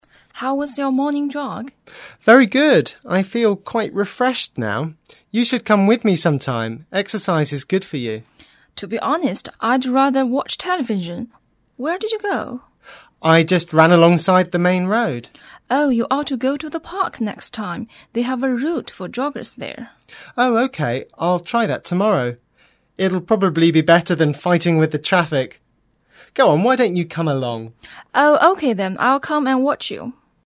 英语初学者口语对话第01集：你今天的晨跑如何？
english_52_dialogue_2.mp3